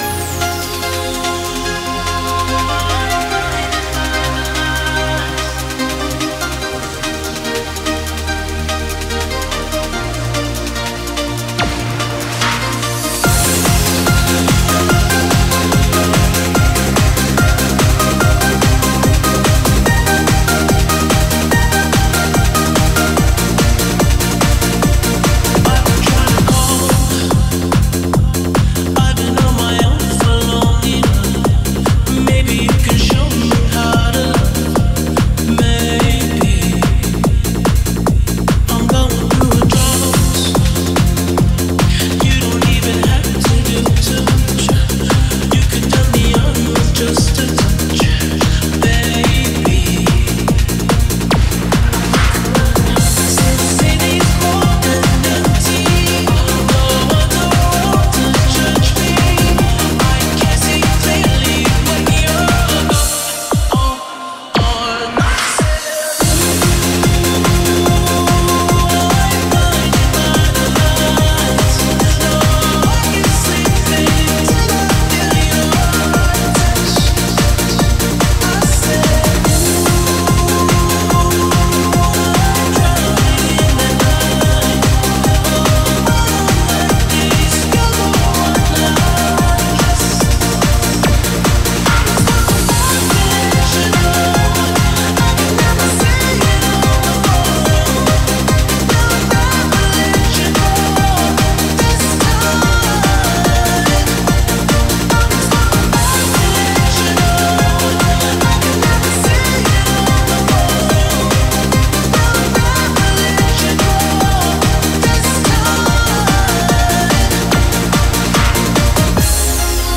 BPM145
Comments[90s EURODANCE]